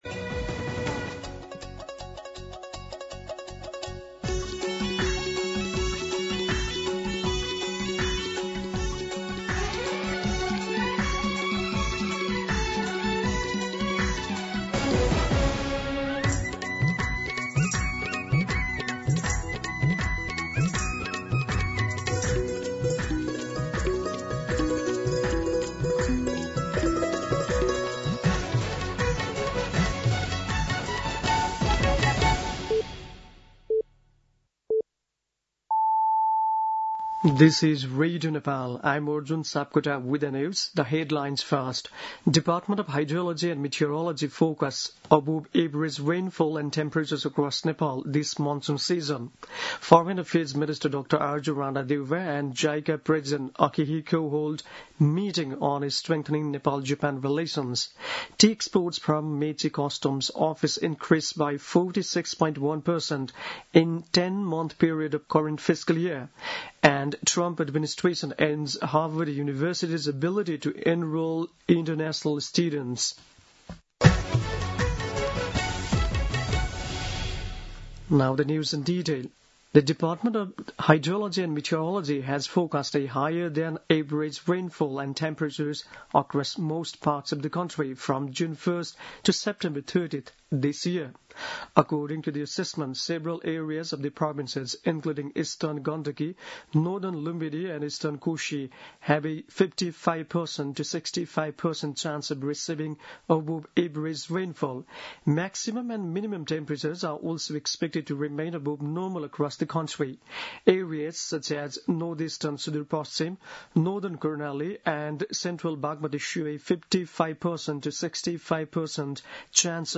दिउँसो २ बजेको अङ्ग्रेजी समाचार : ९ जेठ , २०८२